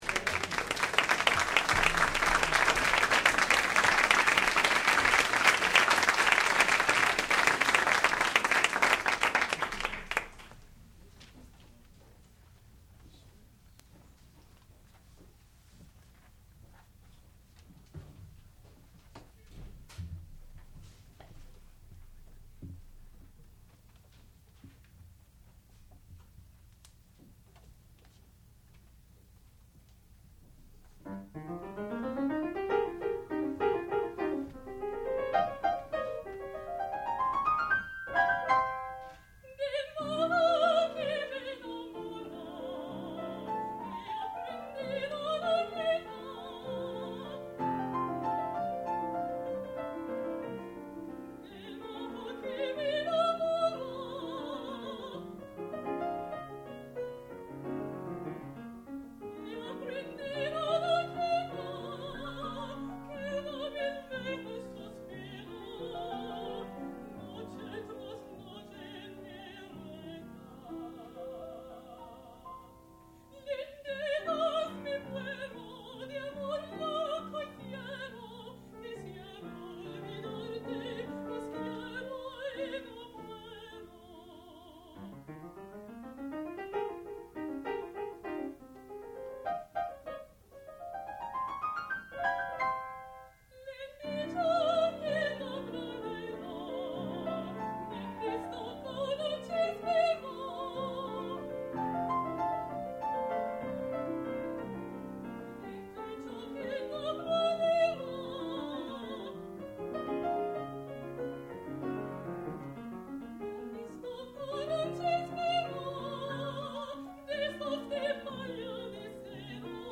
sound recording-musical
classical music
Master's Recital
mezzo-soprano